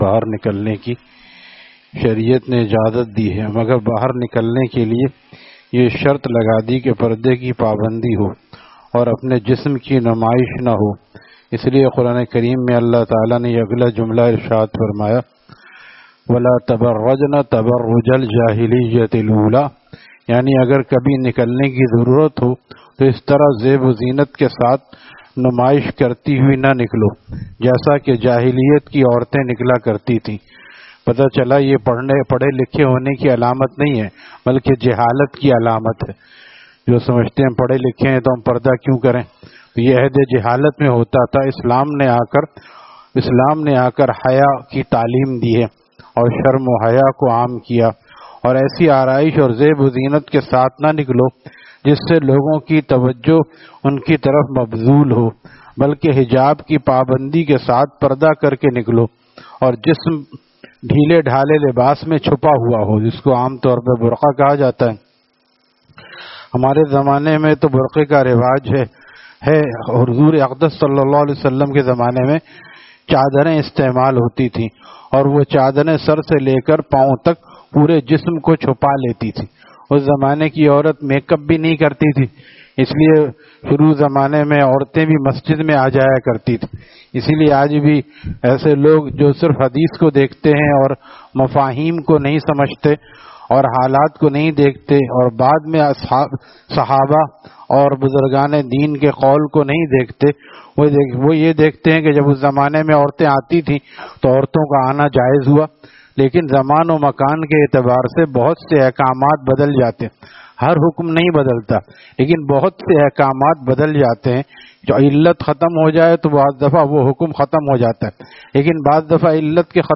Taleem After Fajar at Jamia Masjid Gulzar e Mohammadi, Khanqah Gulzar e Akhter, Sec 4D, Surjani Town